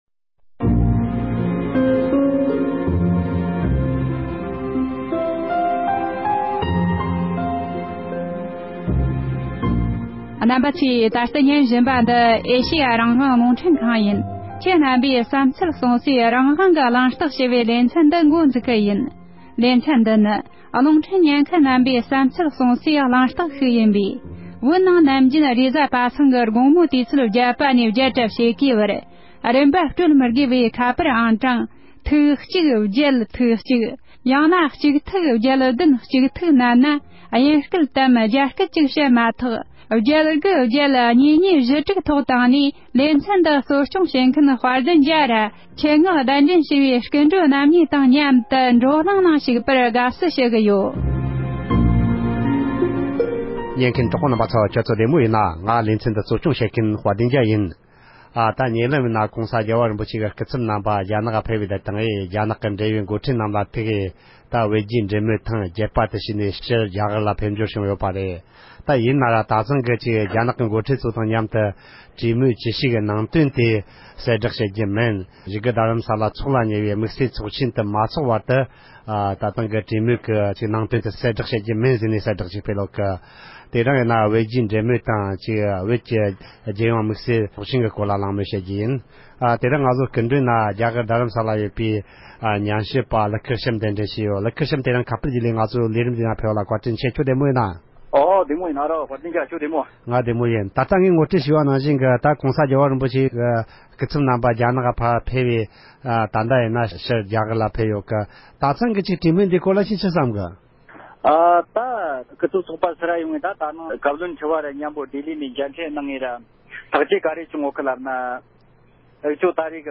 གླེང་མོལ་ཞུས་པ་ཞིག